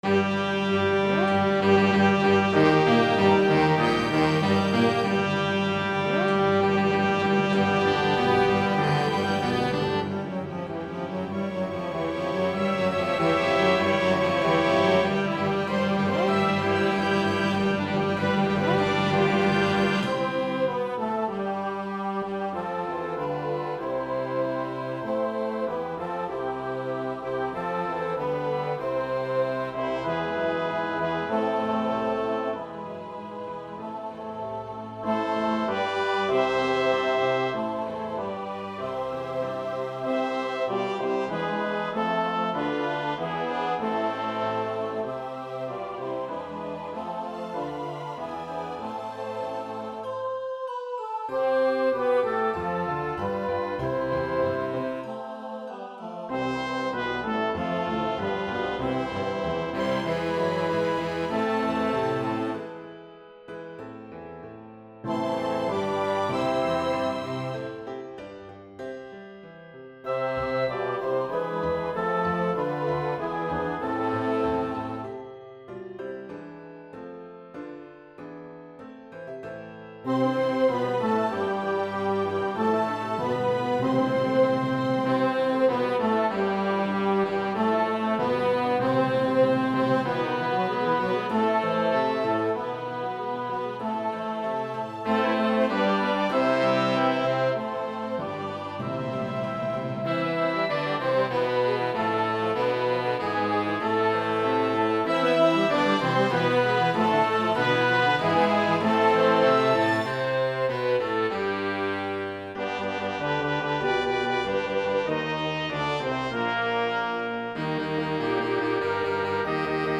My church asked me to do a Christmas arrangement.
is for choir, piano, organ, hand bells and orchestra.
The soprano and alto parts reverse the direction of the melody
There are no retardandos in this version
CHRISTMAS MUSIC